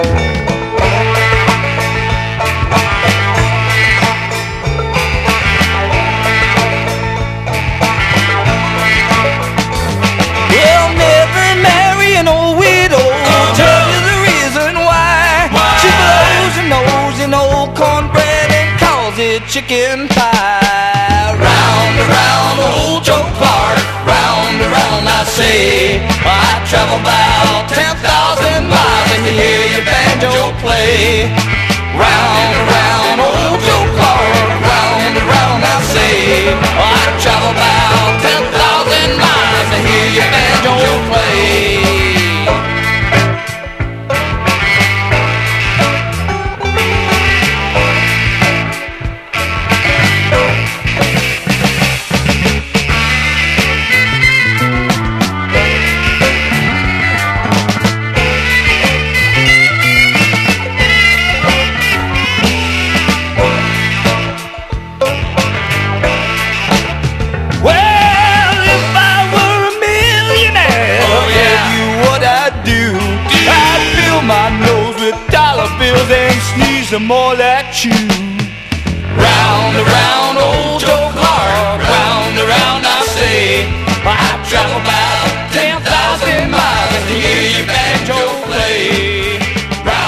ROCK / 70'S / JUG BAND / ACOUSTIC SWING / GOOD TIME MUSIC
小粋なアコースティック・スウィング/ジャグ・バンド！
フィンガースナップ入りの軽やかでスウィンギーな
フィドルが高らかに響く